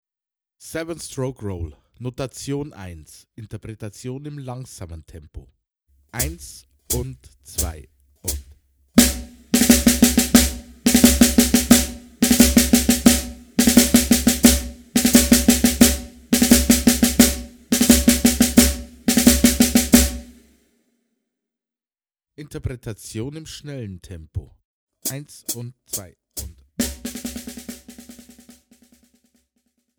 Besetzung: Schlagzeug
04 - 7-Stroke-Roll
04_-_7-Stroke-Roll.mp3